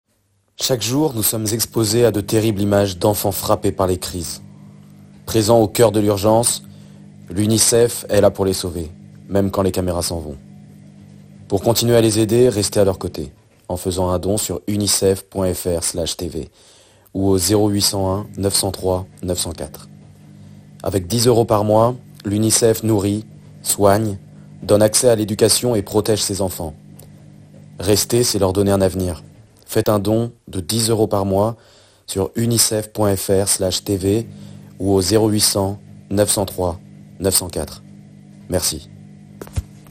Bandes-son
23 - 40 ans - Baryton